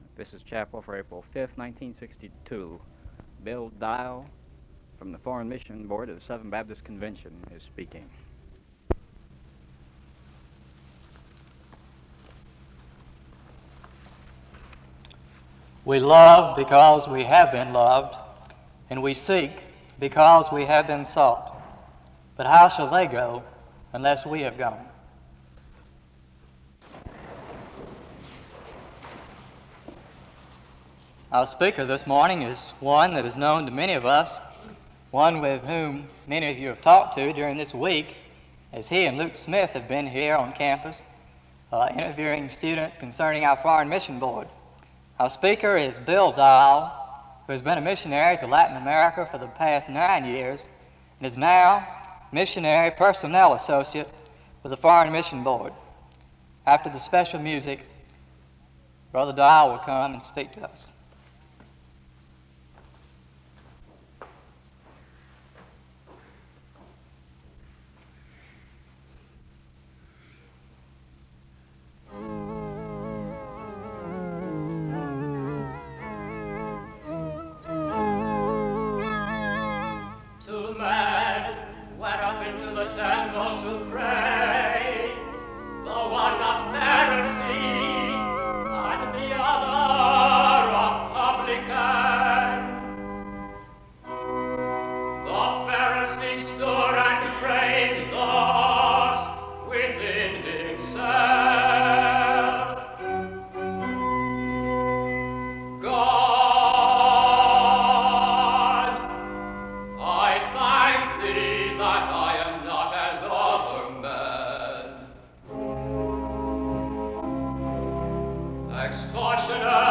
A singing of the parable of the pharisee and the publican precedes the speaker’s message (01:12-05:17).
He ends in prayer (23:39-24:36).
Location Wake Forest (N.C.)